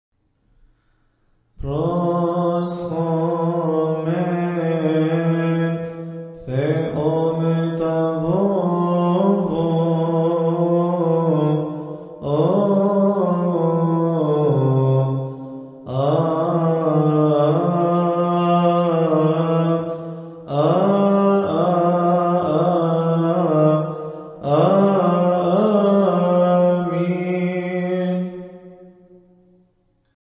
All hymns must be chanted according to the Higher Institute of Coptic Studies.